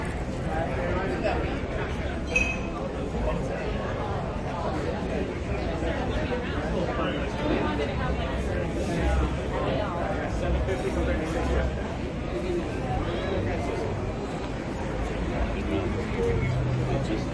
棒球场人声音乐实地录音
描述：这是记录在阿灵顿在ZOOM H2巡游者棒球场。 两个随机的音乐在体育场播放。
标签： 音乐 聊天 球场 人群 风琴 体育运动 实地录音 棒球
声道立体声